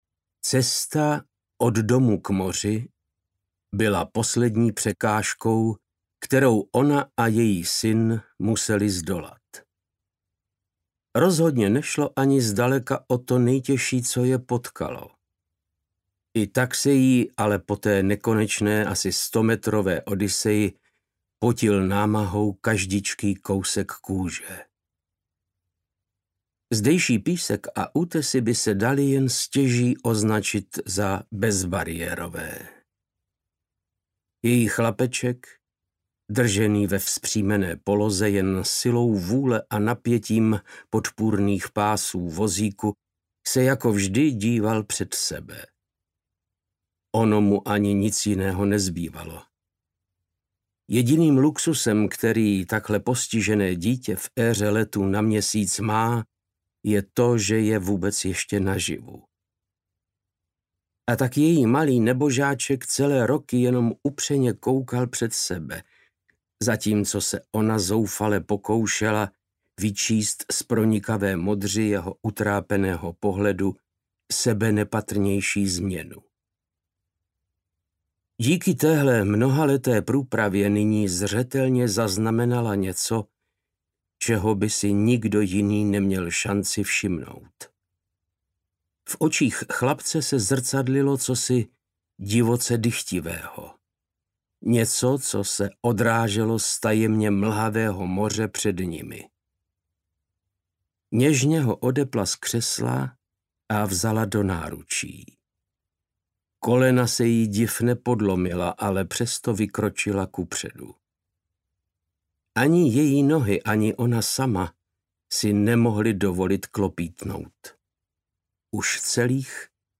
Volání audiokniha
Ukázka z knihy
• InterpretVáclav Knop